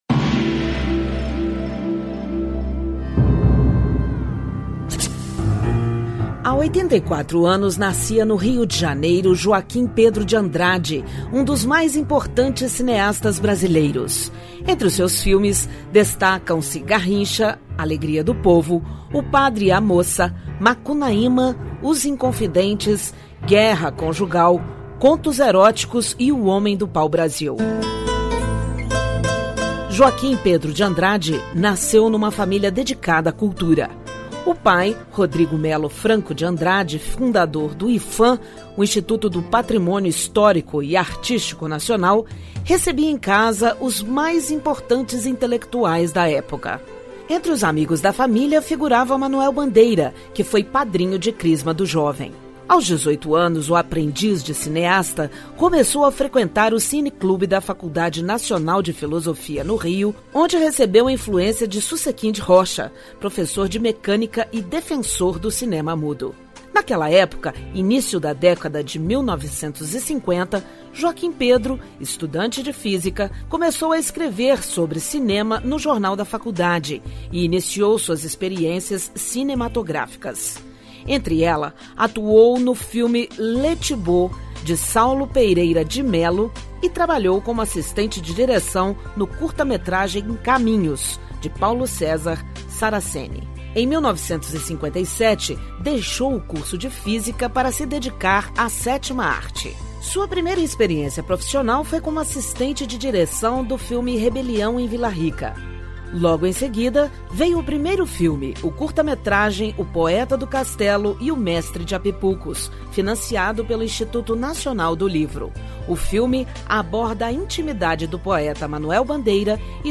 História Hoje: Programete sobre fatos históricos relacionados a cada dia do ano.